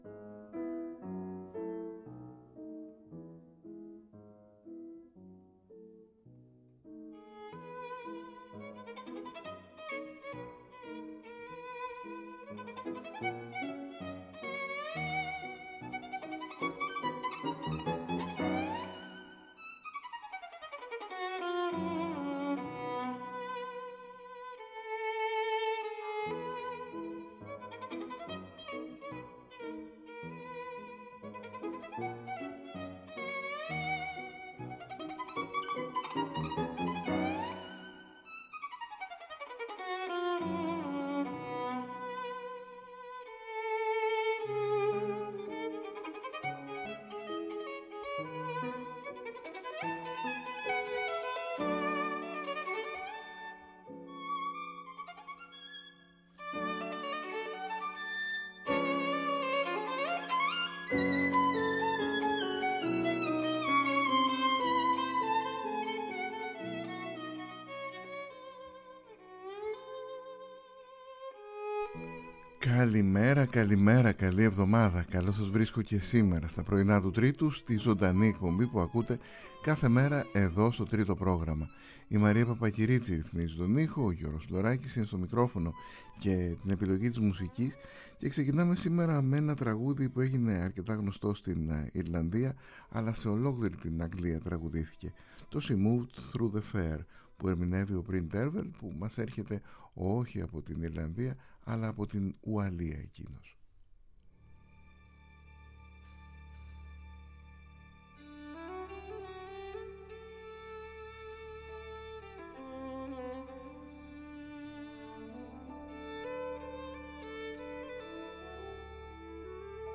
Κάθε πρωί στις 8.00, ζωντανά, οι εκλεκτικές μας αναζητήσεις, ένα είδος -Αισθηματικής Αγωγής- ξεκινούν από την αναγεννησιακή μουσική, περνούν μέσα από το μπαρόκ, την κλασική, τη ρομαντική και τη μοντέρνα εποχή και συναντούν τη jazz, singers-songwriters απ’ όλον τον κόσμο, μινιμαλιστές και μετα-μινιμαλιστές συνθέτες, παραδοσιακούς ήχους από ολόκληρο τον πλανήτη, κινηματογραφικές επενδύσεις, θεατρικές ηχογραφήσεις και σύγχρονες μουσικές δημιουργίες που ξεπερνούν κάθε κατηγοριοποίηση.